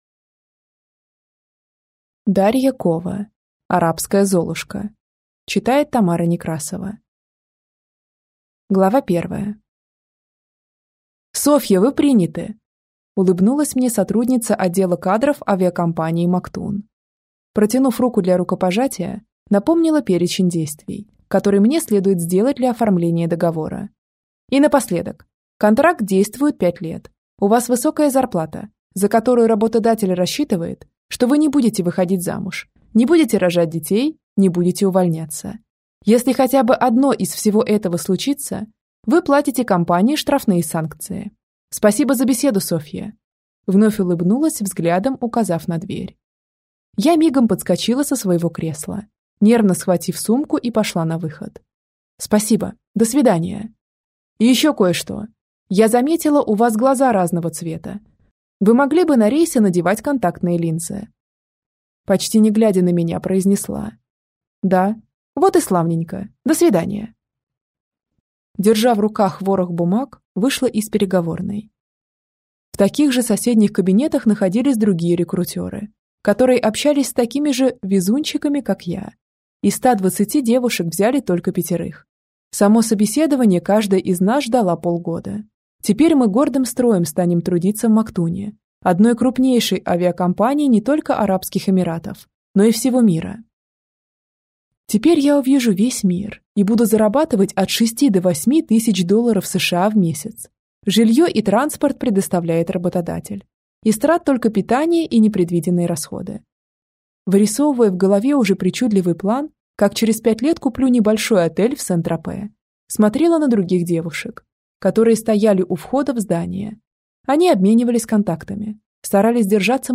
Аудиокнига Арабская Золушка | Библиотека аудиокниг